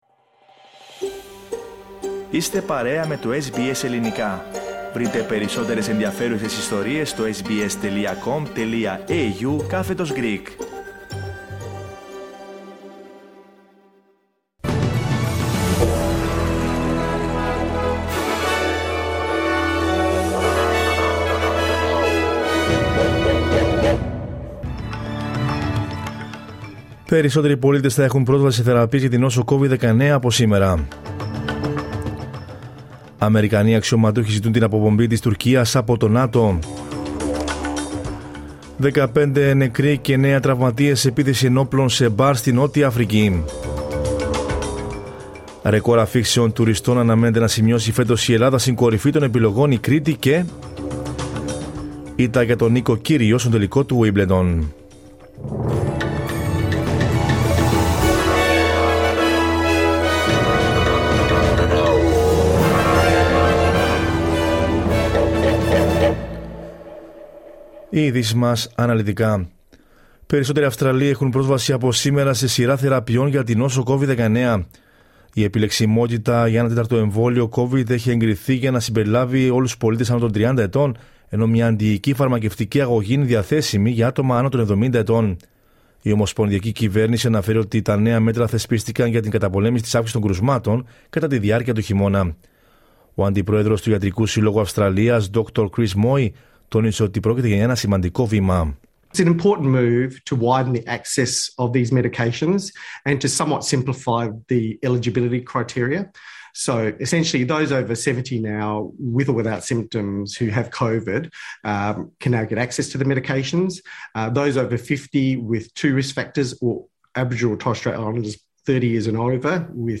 News in Greek from Australia, Greece, Cyprus and the world is the news bulletin of Monday 11 July 2022.